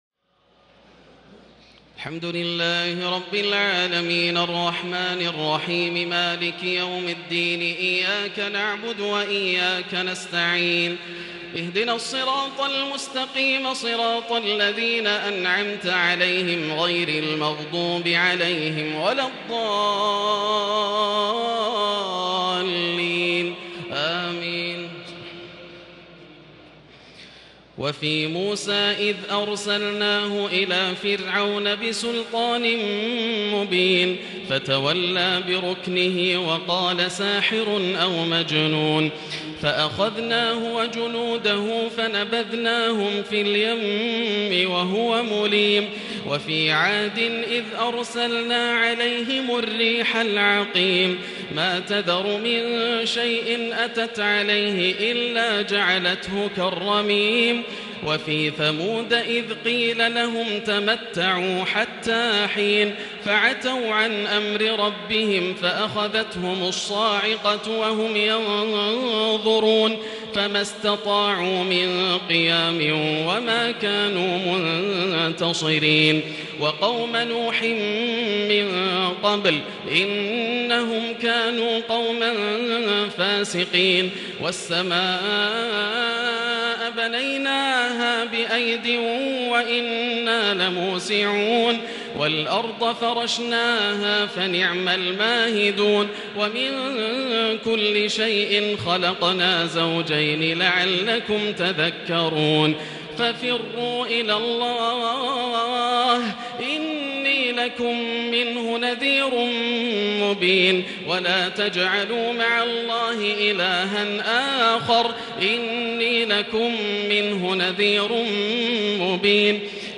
تراويح ليلة 26 رمضان 1440هـ من سور الذاريات(38-60) و الطور و النجم و القمر Taraweeh 26 st night Ramadan 1440H from Surah Adh-Dhaariyat and At-Tur and An-Najm and Al-Qamar > تراويح الحرم المكي عام 1440 🕋 > التراويح - تلاوات الحرمين